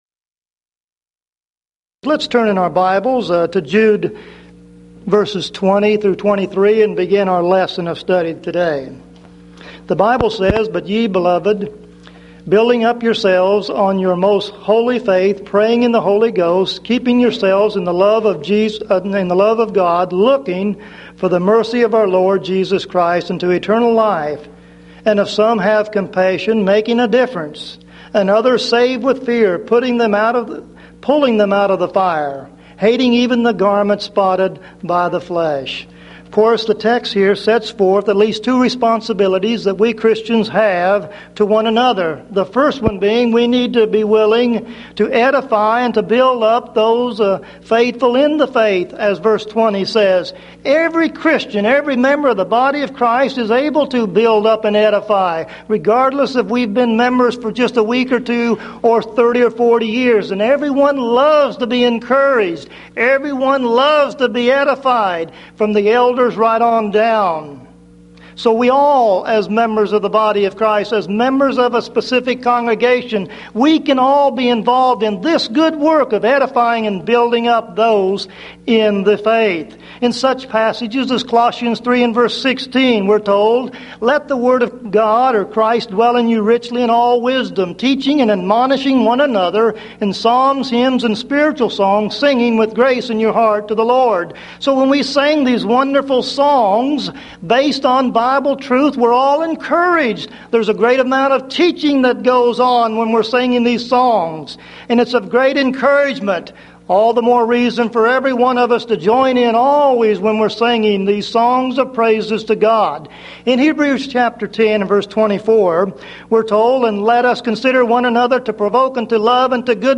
Event: 1994 Mid-West Lectures
lecture